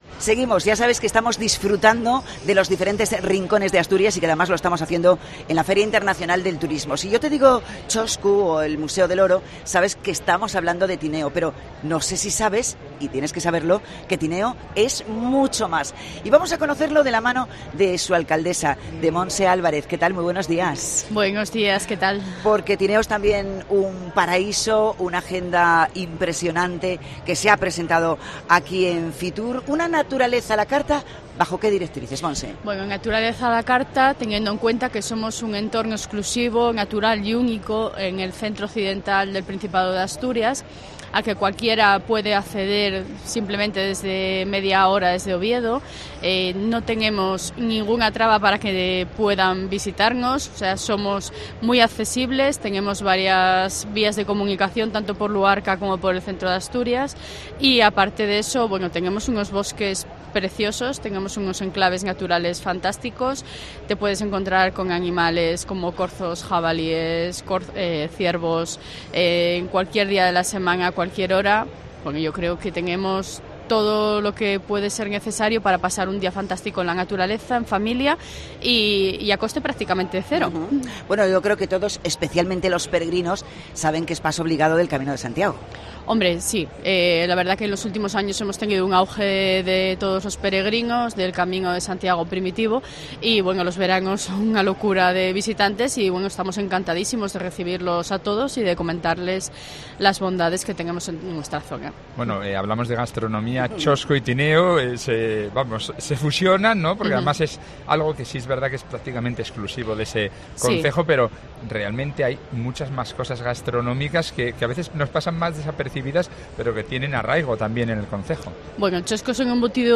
FITUR 2024: Entrevista a Montserrat Fernández, alcaldesa de Tineo